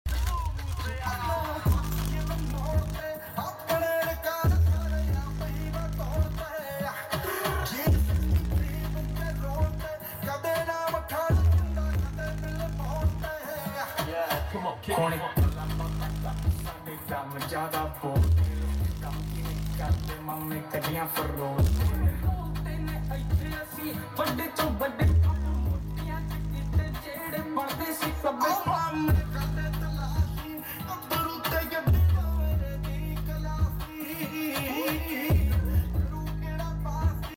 sound system testing